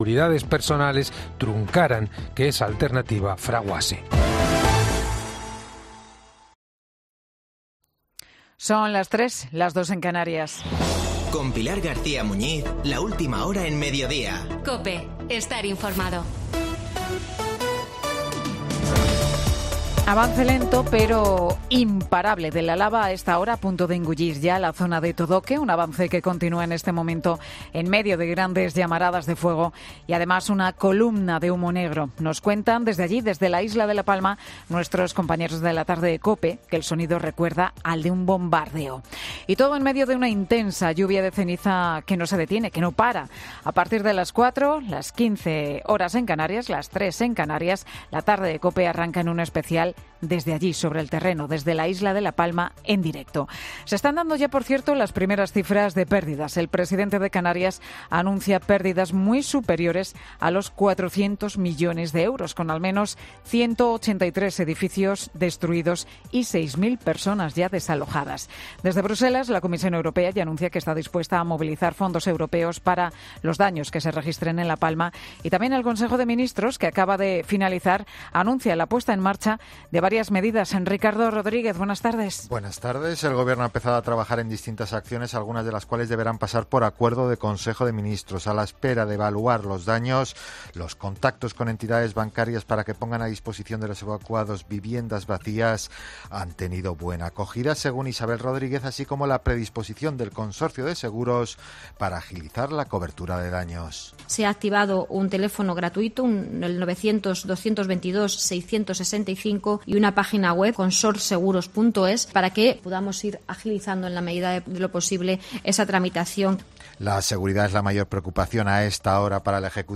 Boletín de noticias COPE del 21 de septiembre 2021 a las 15:00 horas